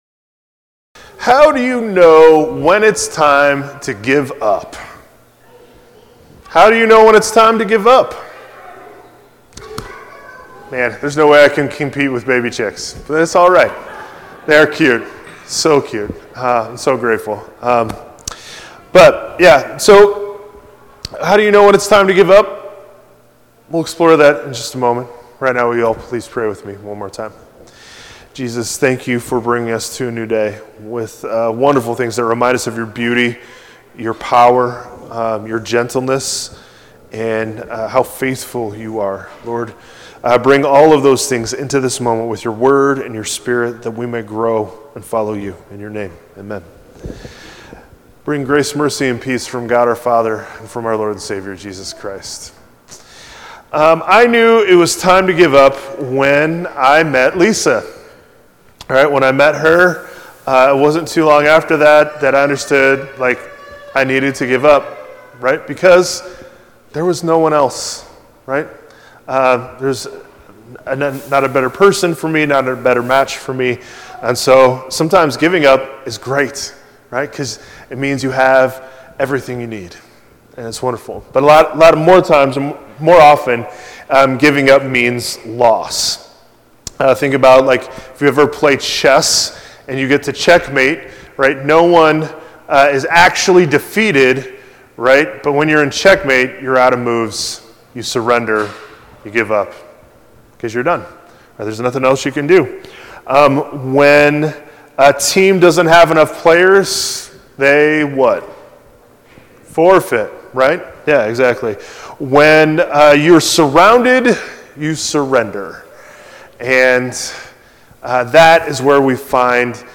March-8-2026-sermon.mp3